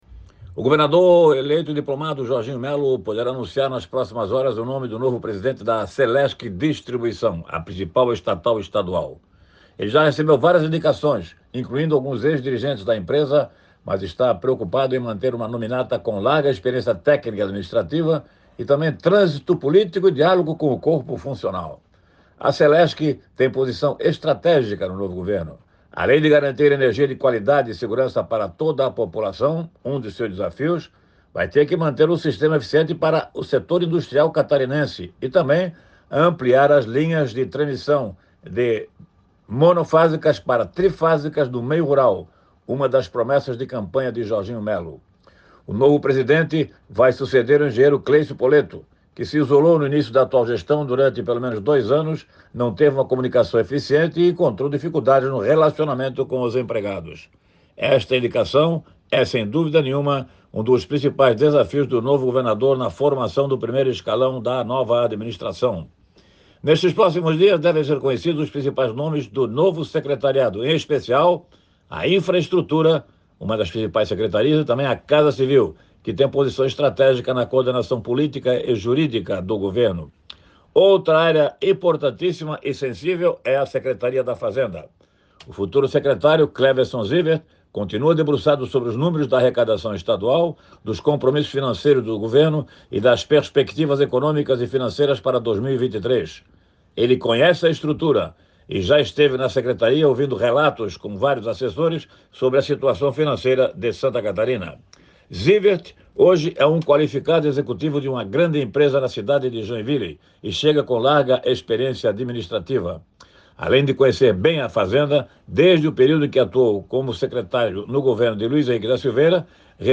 Jornalista comenta que a Celesc tem uma posição estratégica no governo de Santa Catarina, mas algumas promessas de campanha sobre os serviços da estatal irão desafiar a próxima gestão
Acompanhe o comentário: